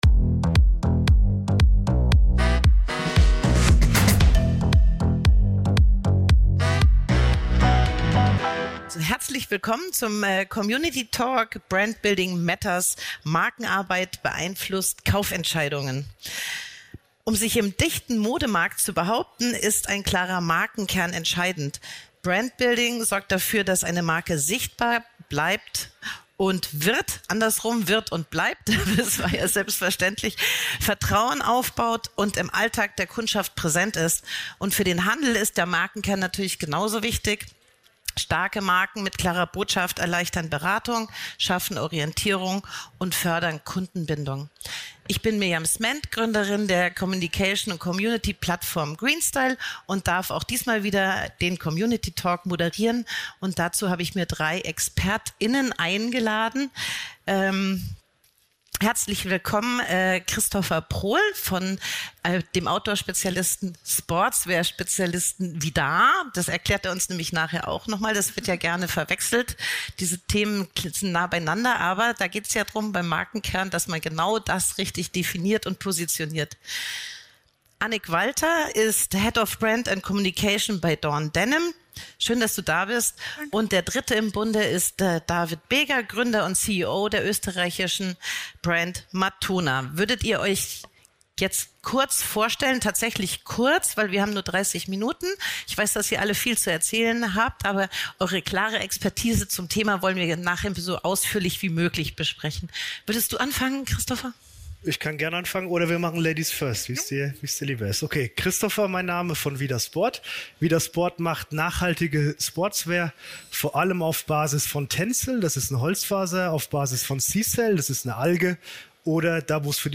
Dass Brand Building keine Worthülse ist, darauf geht dieser Talk ein. Im Mittelpunkt stehen konkrete Hebel, beispielsweise wie Marken herausfinden, was ihre Kundschaft wirklich wahrnimmt und erwartet. Plus: Best Practice, Tools, Fragen und Routinen.